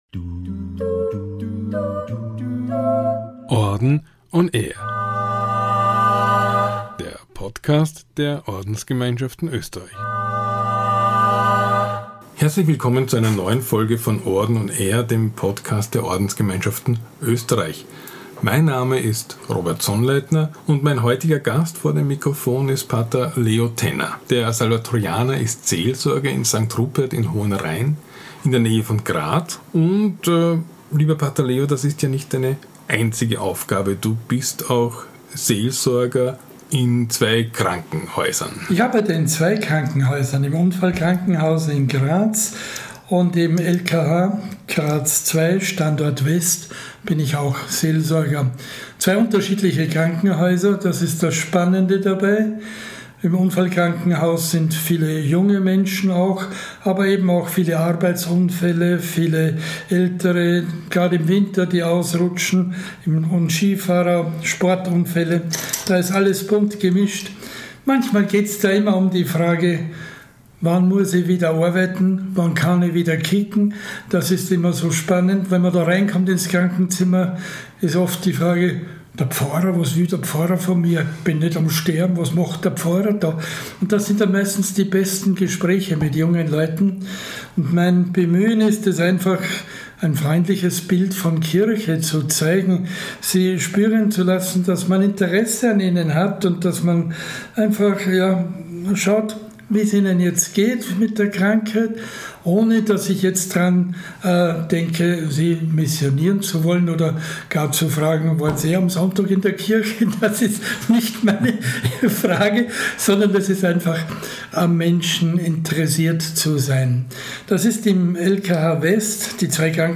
„Orden on air“ – der Podcast der Ordensgemeinschaften Österreich holt Ordensfrauen und -männer vor den Vorhang und – im wahrsten Sinne des Wortes – vor das Mikrofon. Ziel ist es, interessante Persönlichkeiten und besondere Talente vorzustellen sowie das Engagement von Ordensleuten in den vielfältigen Bereichen des Lebens zu zeigen.